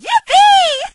shelly_kill_03.ogg